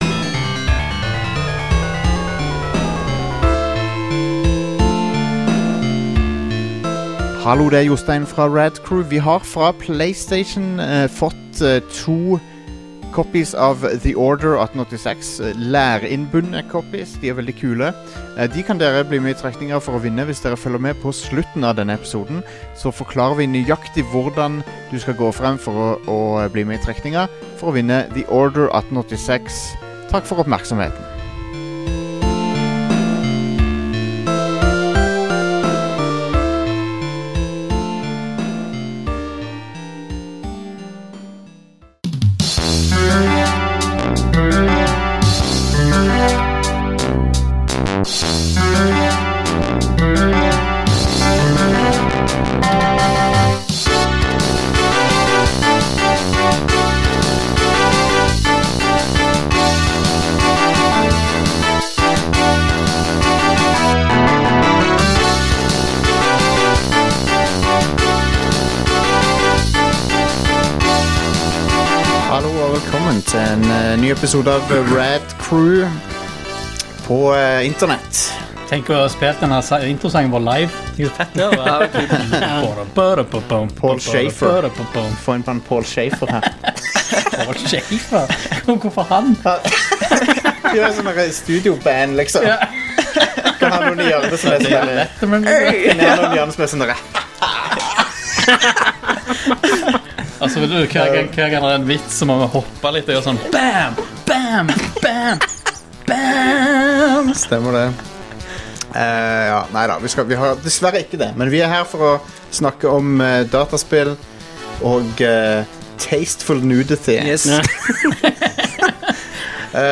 Rad Crew blir skrur opp temperaturen i ukens episode med en topp fem-liste over spill som bydde på overraskende innslag av nakenhet – overraskende, men ofte ganske uønsket også. Vi spurte også lytterne om det i det hele tatt går an å inkludere nakenscener i spill uten at det blir kleint.